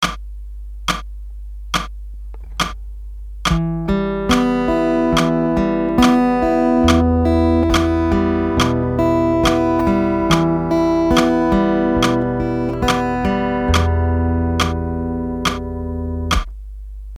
Fingerpicking E Minor Chord Exercise
Fingerpicking E Minor Arpeggios | Download
The aim of all these fingerpicking exercises in this guitar lesson is to create a smooth flowing sound with all your plucking fingers playing at the same volume and in time.
fingerpicking_eminor.mp3